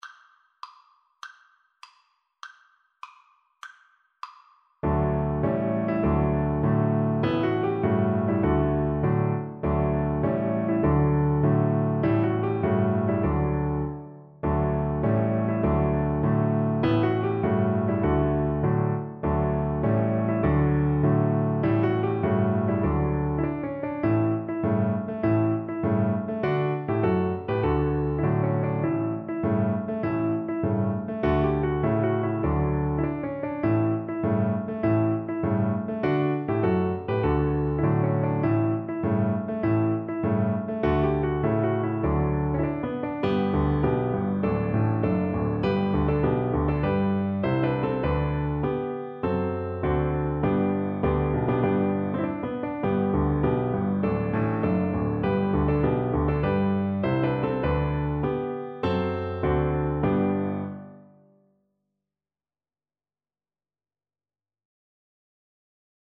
Violin
D major (Sounding Pitch) (View more D major Music for Violin )
March
2/4 (View more 2/4 Music)
Classical (View more Classical Violin Music)
philippine_nat_vln_kar1.mp3